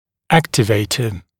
[‘æktɪveɪtə][‘эктивэйтэ]активатор; возбудитель